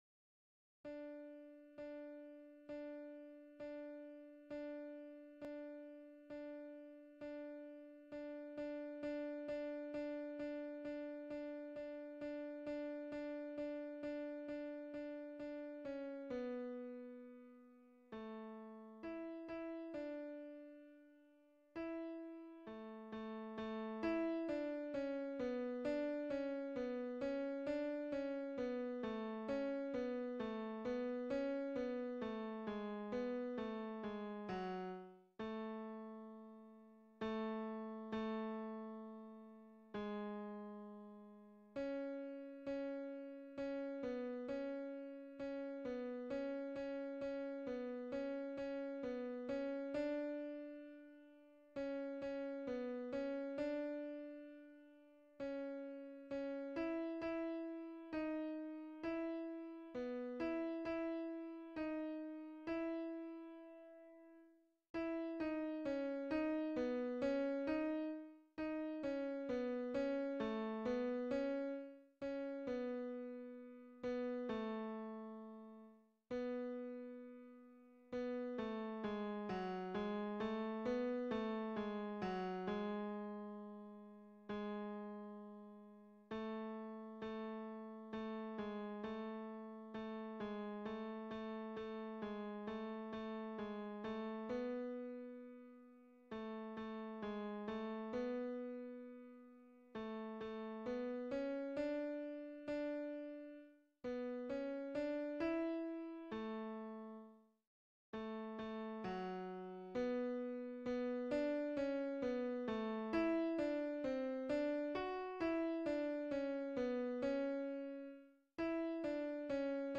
MP3 version piano
Tenor